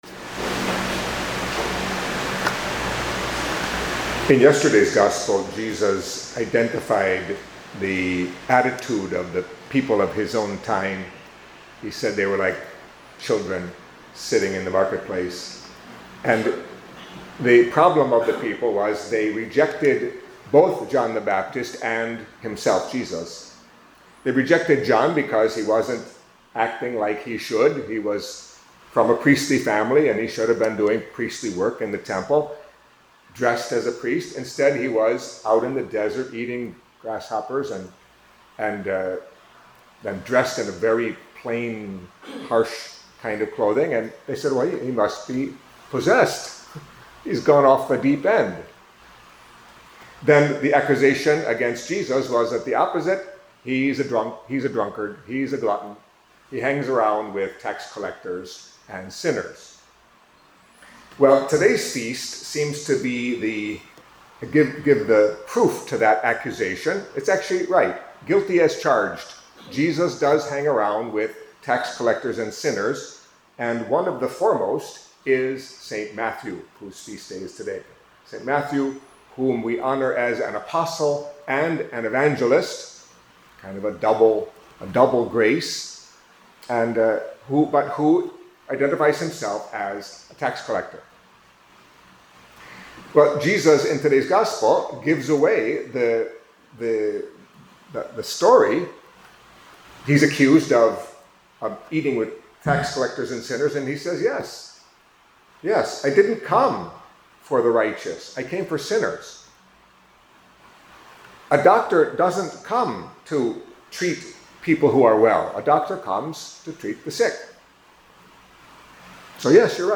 Catholic Mass homily for St Matthew